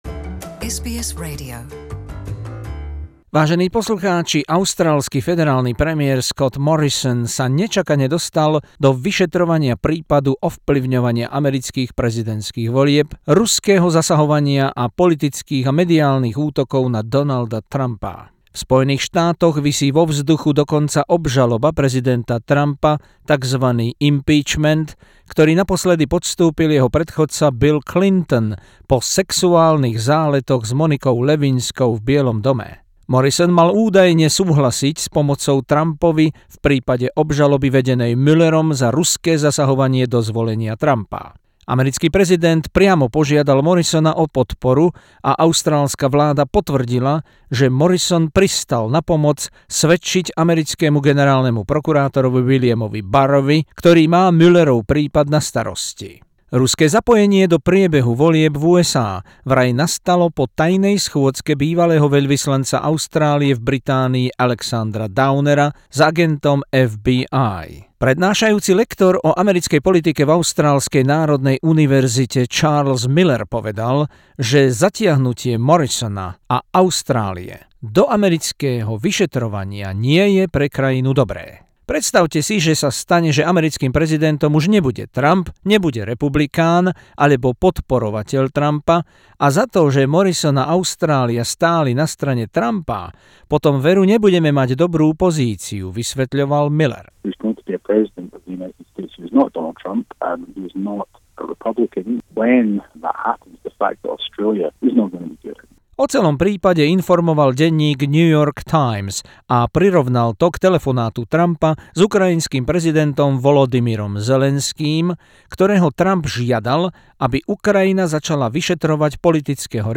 Zo spravodajskej dielne SBS.